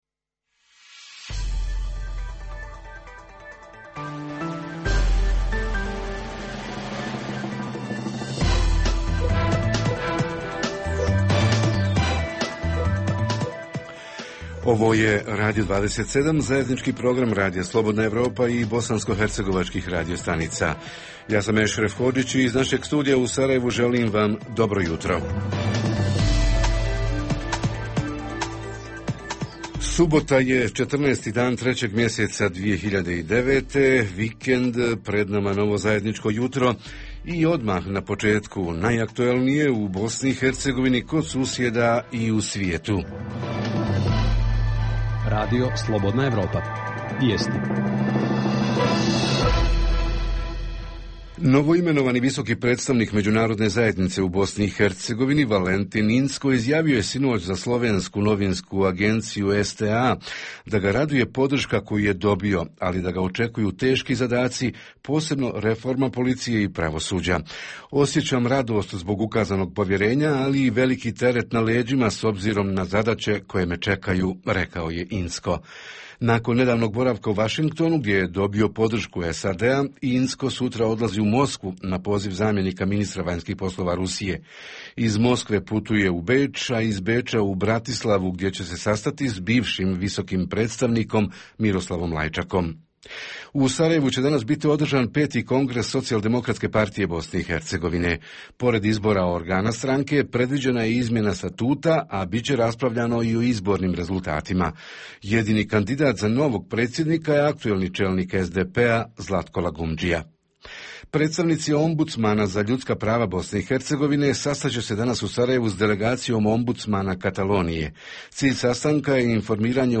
Jutarnji program za BiH koji se emituje uživo pita: privredni objekti i postrojenja - i ekologija – ko ima, a ko nema i kada će dobiti ekološku dozvolu?
Redovni sadržaji jutarnjeg programa za BiH su i vijesti i muzika.